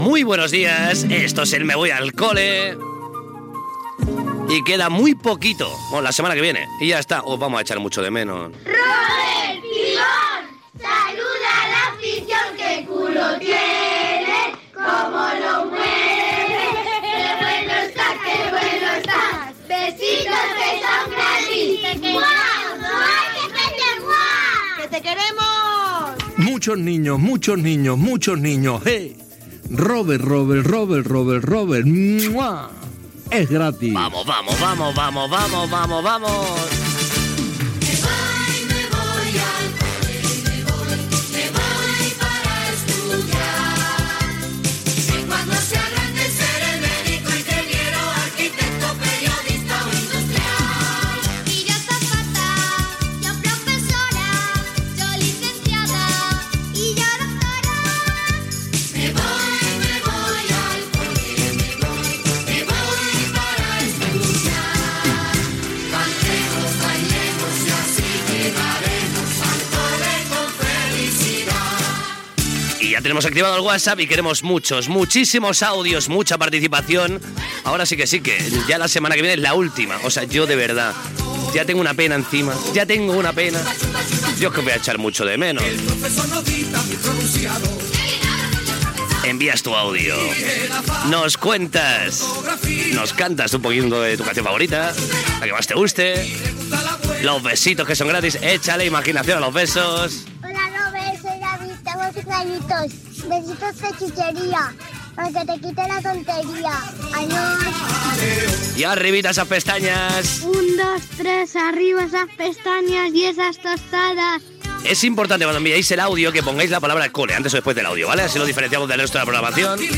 Secció "Me voy al cole", dedicada als nens. Presentació, cançó de l'espai, invitació a participar, missatges d'alguns nens i nenes, telèfon de participació, tema musical
Infantil-juvenil